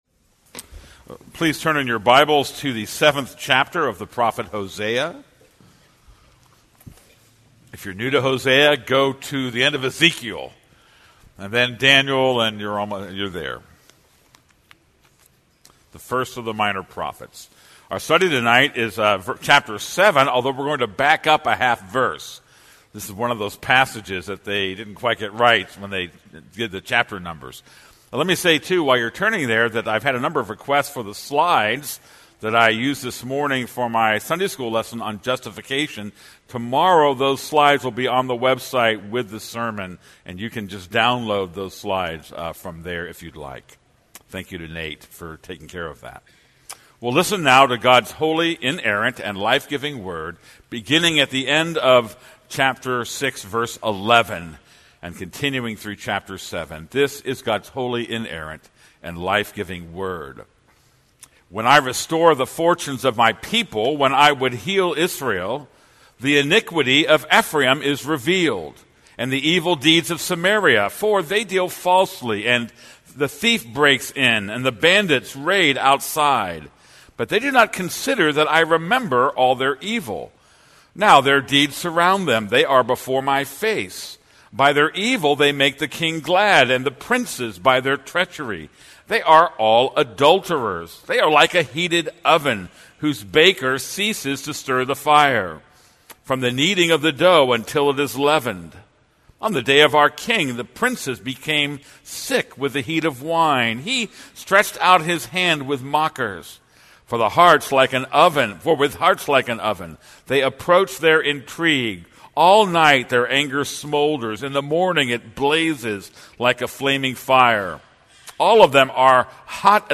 This is a sermon on Hosea 7:1-16.